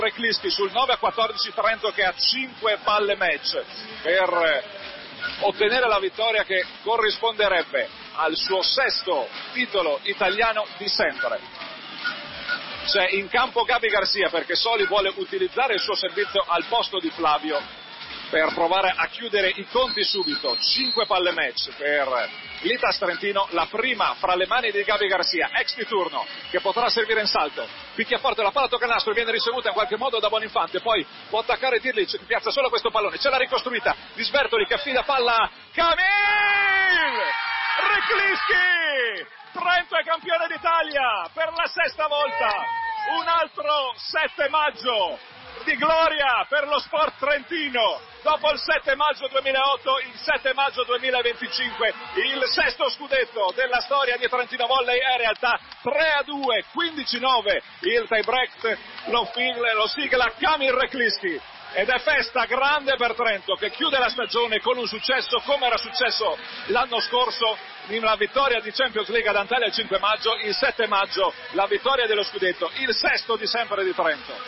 Last points the radio commentary
Radiocronache ultimi punti